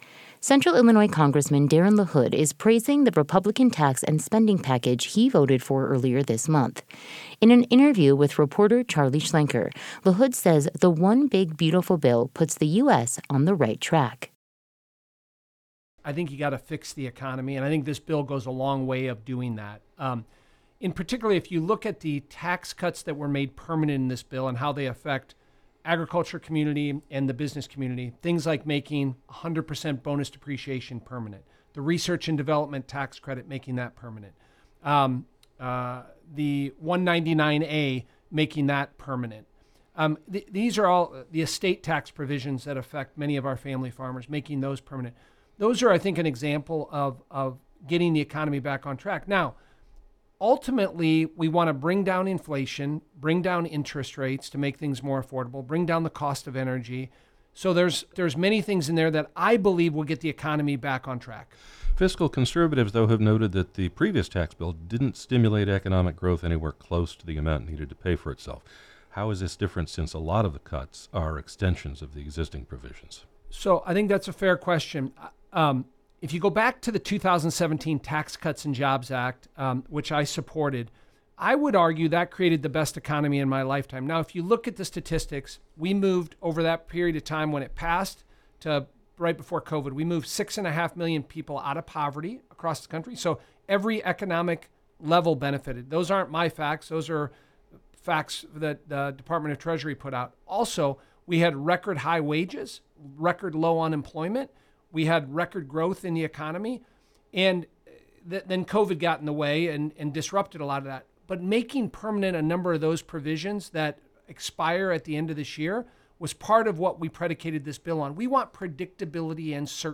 U.S. Rep. Darin LaHood during an interview at WGLT's studios in Normal on Wednesday, July 9, 2025.